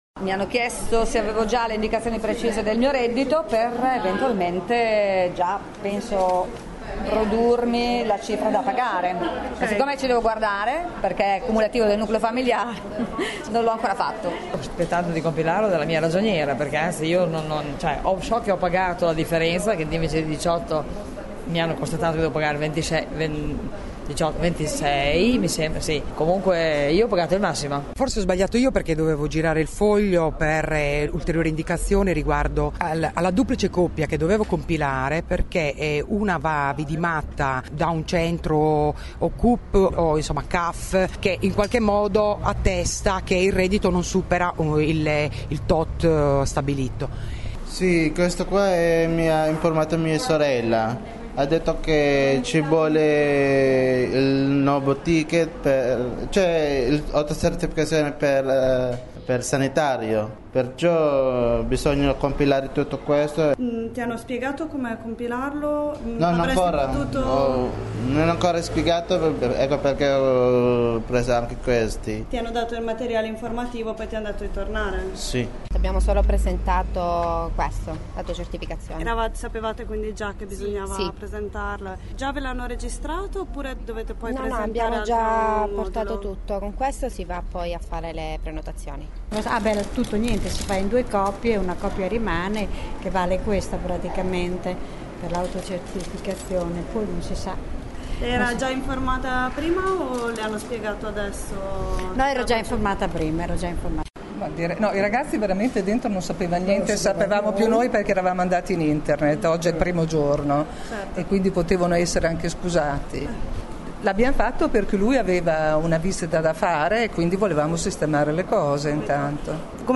Ascolta le voci degli utenti impegnati nella presentazione dell’autocertificazione, questa mattina al Cup di Via Montebello.
voci_ticket.mp3